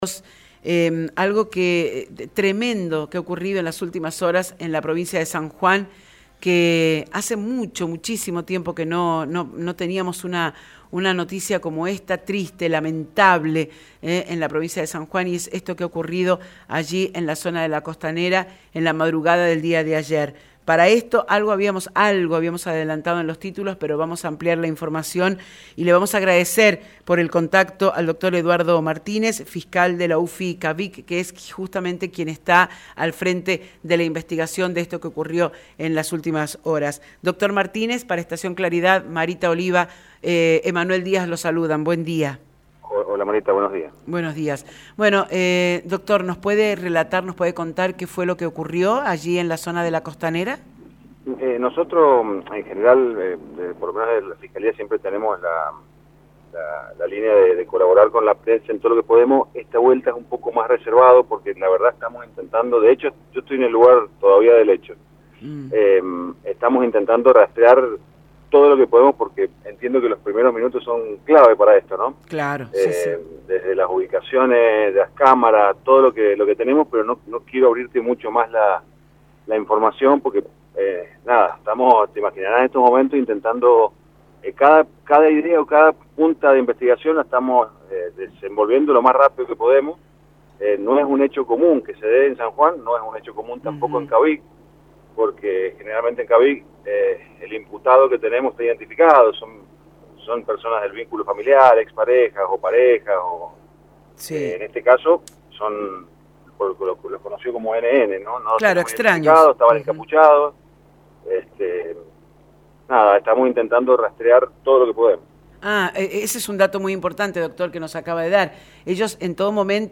Este viernes el fiscal de la UFI CAVIG, Dr. Eduardo Martinez, brindó detalles en Estación Claridad sobre el ataque sexual que vivió una pareja en la madrugada de este jueves (sobre las 2 am.).